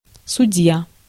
Ääntäminen
IPA: [sʊ.ˈdʲja]